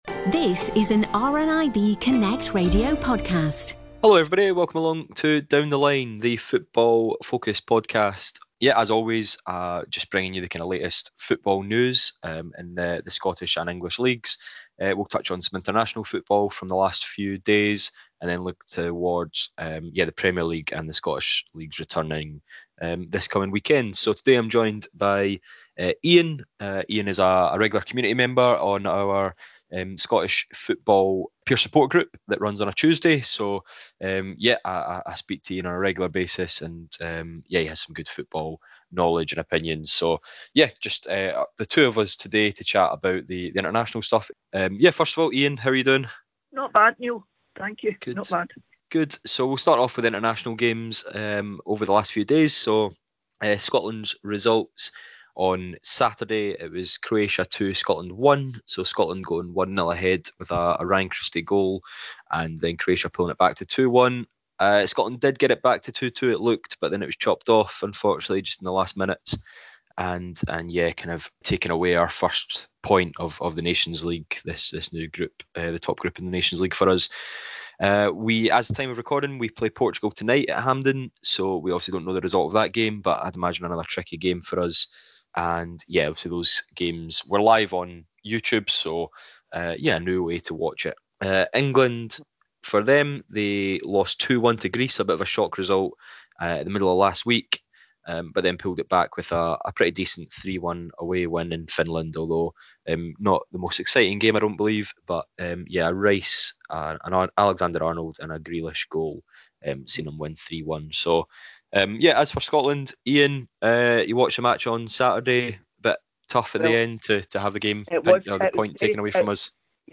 Headliner Embed Embed code See more options Share Facebook X Subscribe Football-loving members of RNIB ‘Community Connections’ telephone groups get together each week to talk about the latest going on in leagues across Scotland and England. This time, looking back at some of the recent international fixtures in the Nations League, plus a look ahead to this weekend’s domestic fixtures.